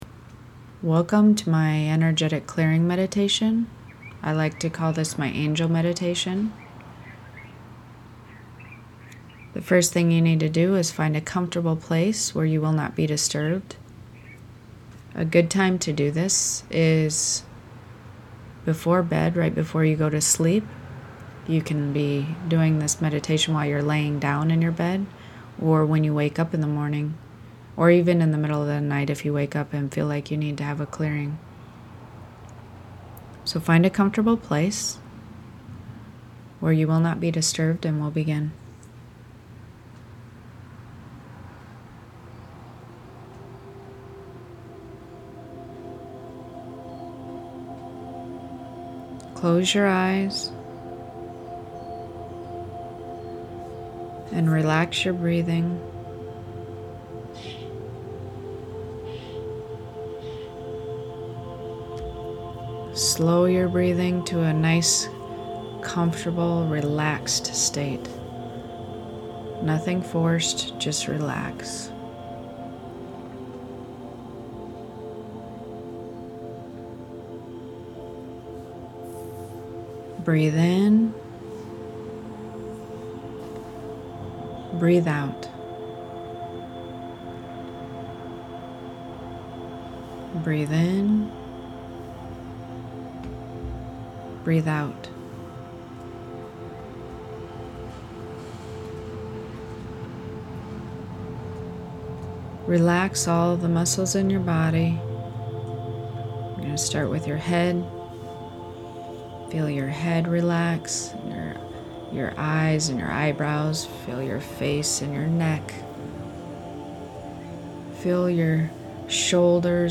Angel_Clearing+Meditation.mp3